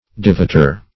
Devoter \De*vot"er\, n. One who devotes; a worshiper.